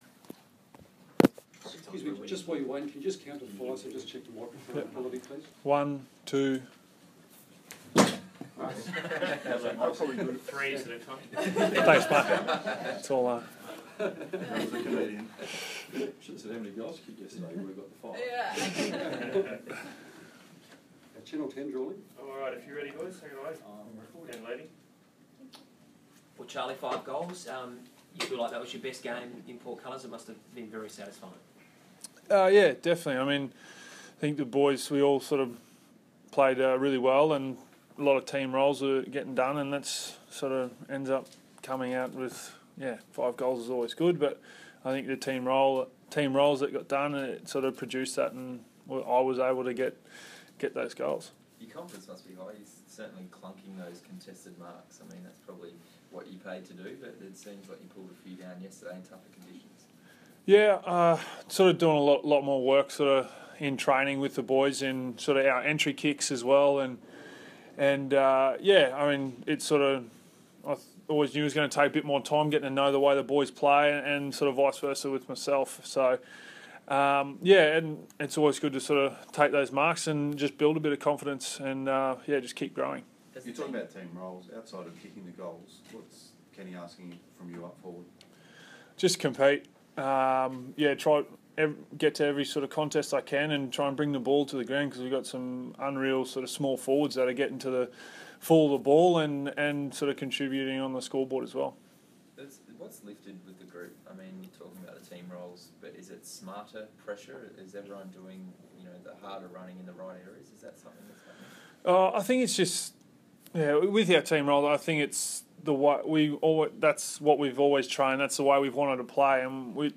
Charlie Dixon Press Conference - Monday, 9 May, 2016